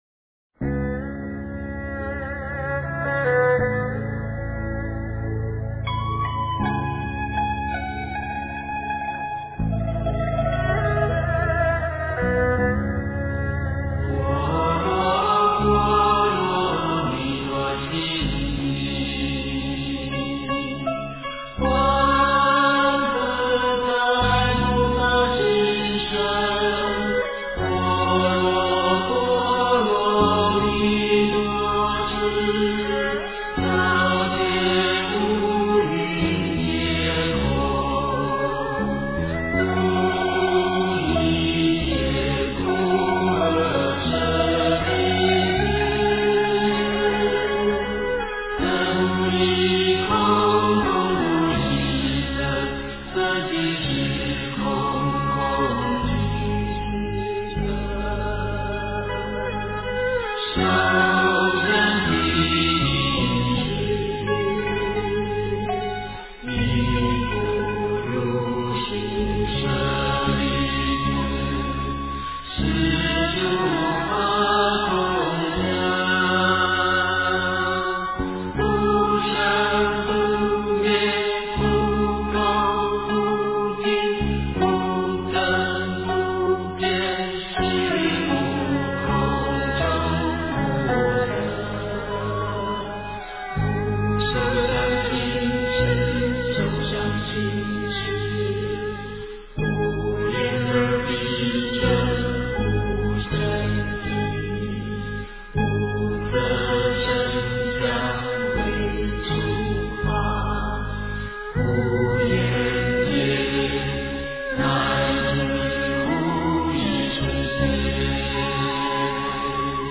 般若波罗密多心经-梵唱 诵经 般若波罗密多心经-梵唱--经典念 点我： 标签: 佛音 诵经 佛教音乐 返回列表 上一篇： 心经 下一篇： 般若波罗蜜多心经 相关文章 Aad Guray Nameh--Snatam Kaur-冥想 Aad Guray Nameh--Snatam Kaur-冥想...